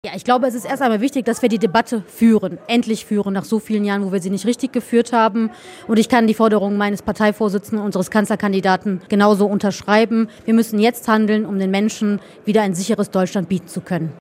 Die Hagener CDU hatte gestern Wahlkampfabend im Arcadeon.